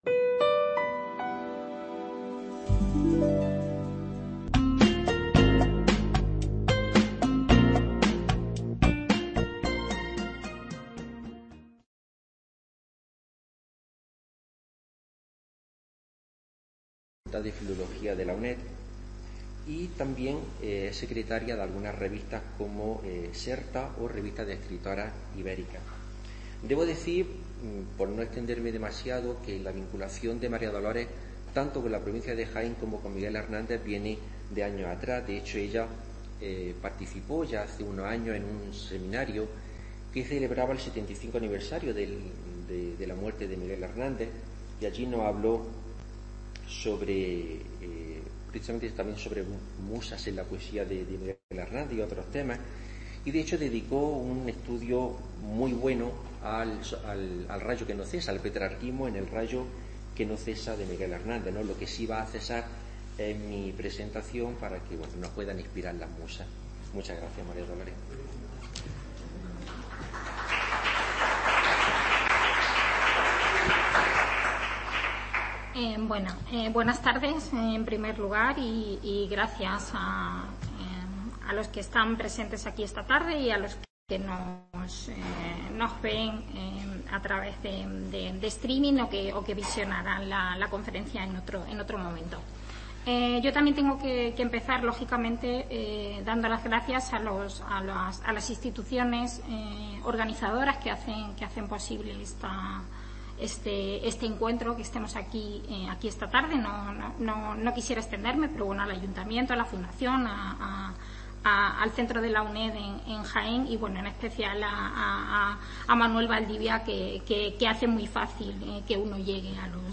conferencia en el Palacio de los Niños de Don Gome (Andújar)